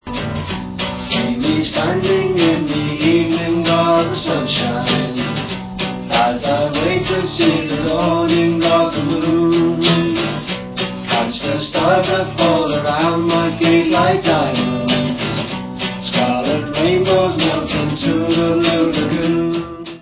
sledovat novinky v kategorii Pop